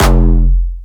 Jumpstyle Kick Solo